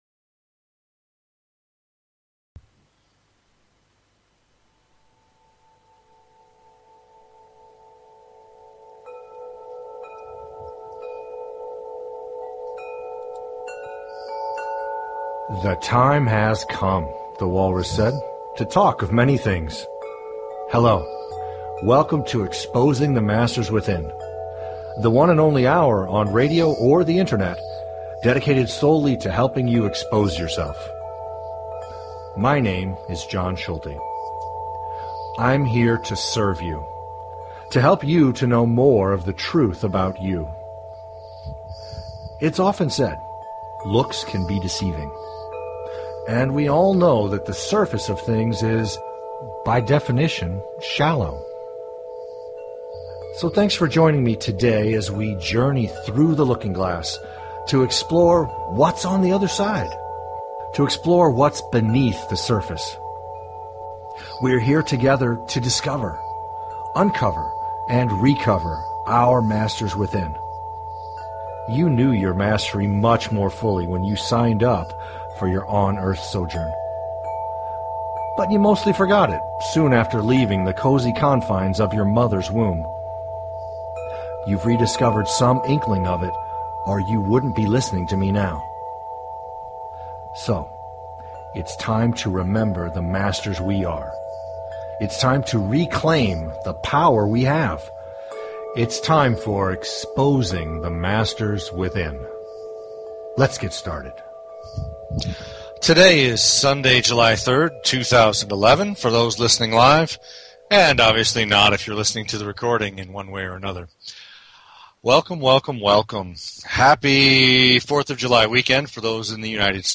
Talk Show Episode, Audio Podcast, Exposing_the_Masters_Within and Courtesy of BBS Radio on , show guests , about , categorized as